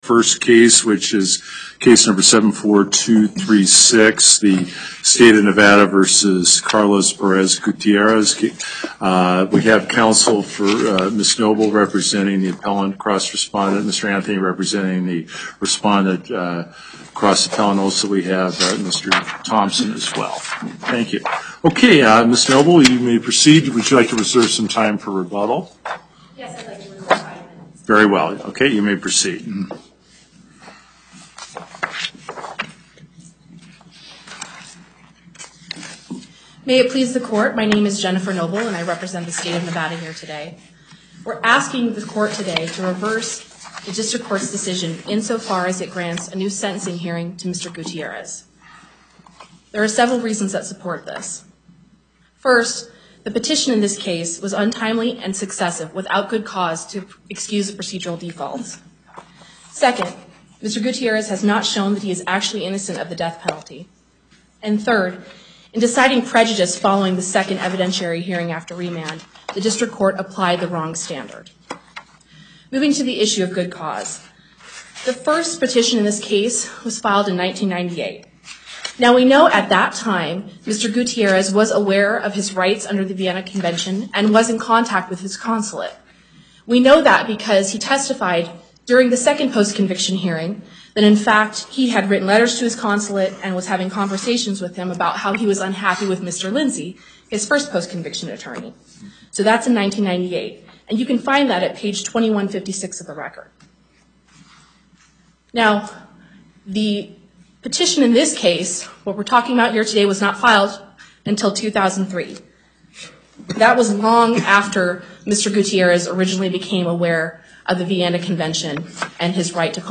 Location: Carson City Before the En Banc court, Chief Justice Gibbons Presiding